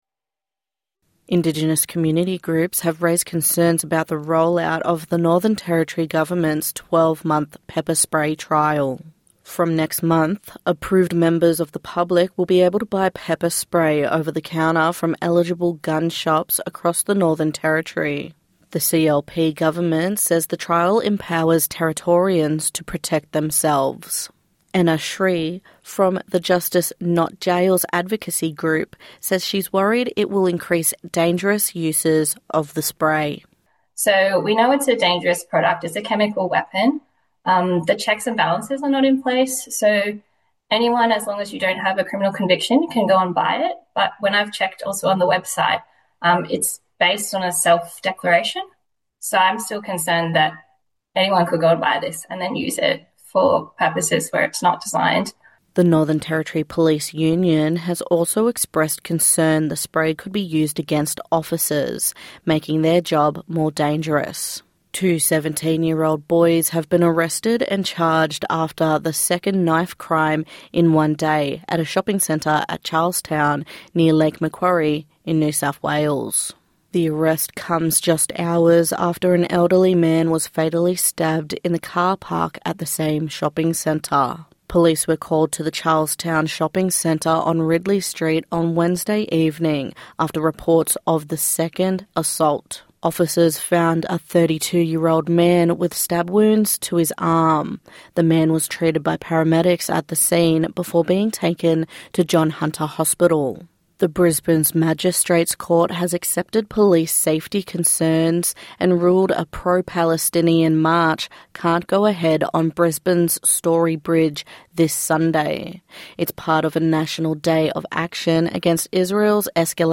NITV Radio News - 22/08/2025